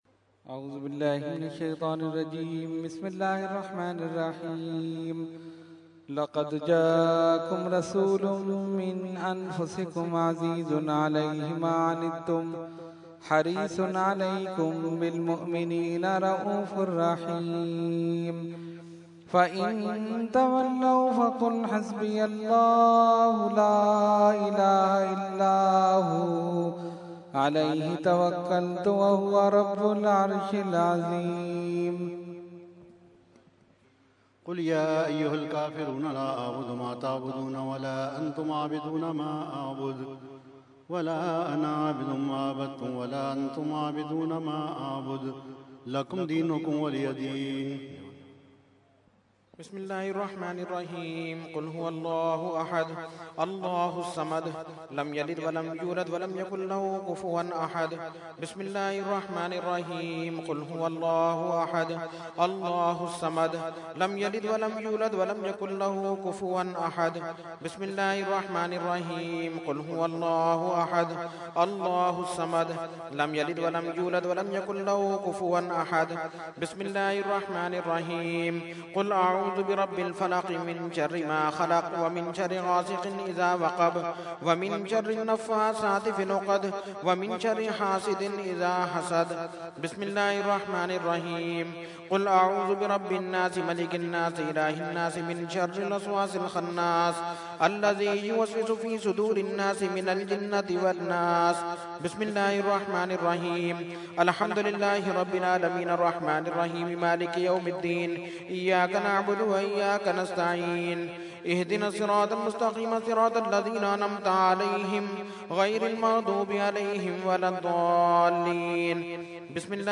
00-Starting Fatiha and Shajrah.mp3